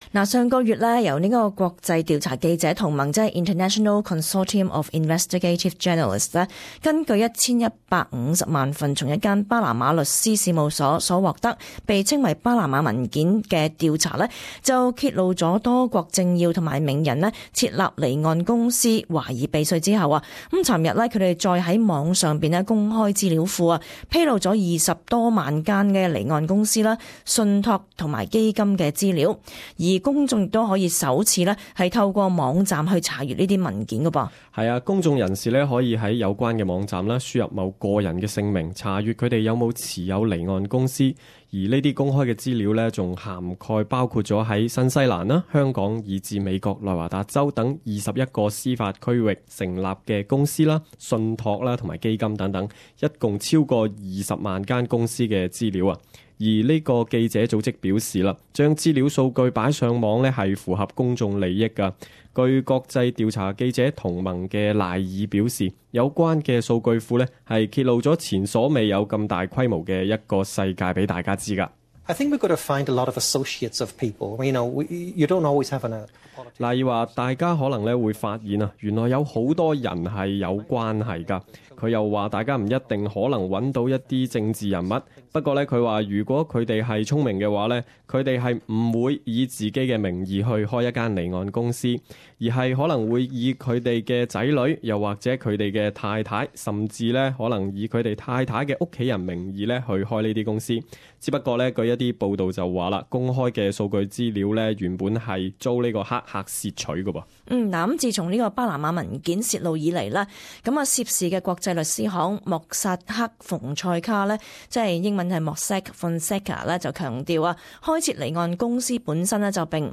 時事報導 － 巴拿馬文件數據庫向大眾公開